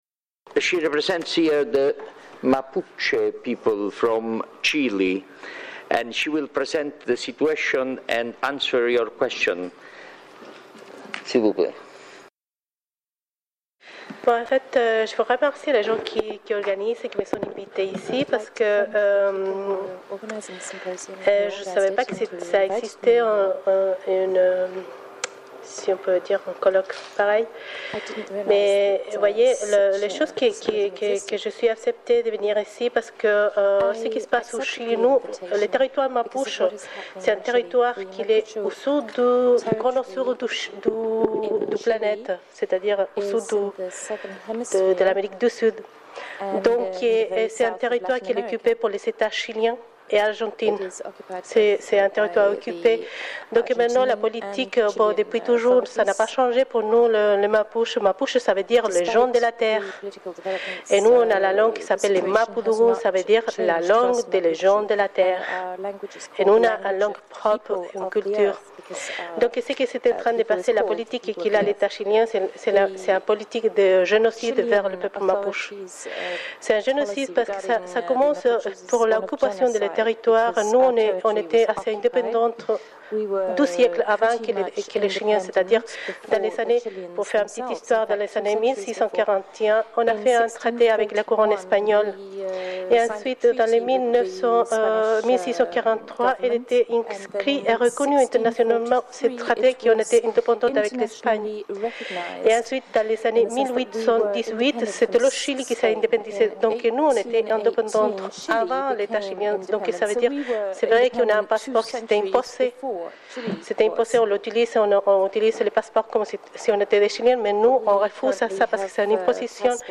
Symposium international
Nations Unies, Genève,